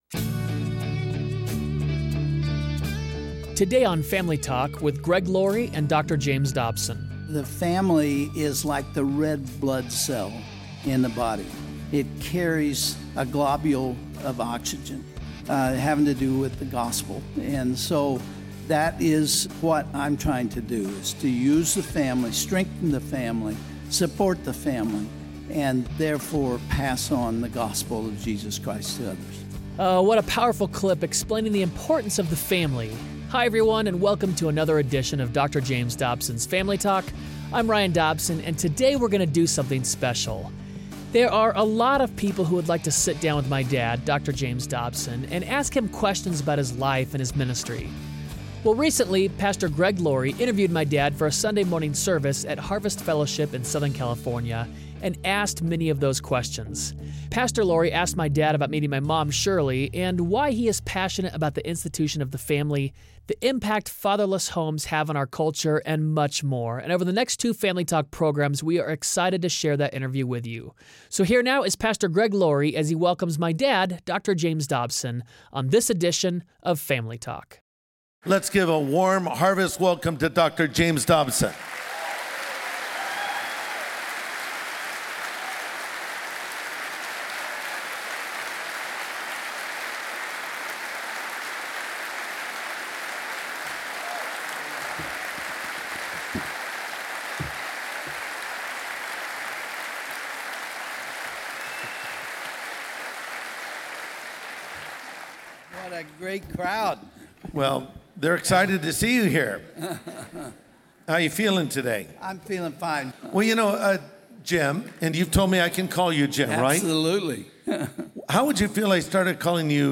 Have you ever wanted to sit down with Dr. Dobson and ask him questions about his life and ministry? Pastor Greg Laurie sits down with Dr. Dobson for a Q&A.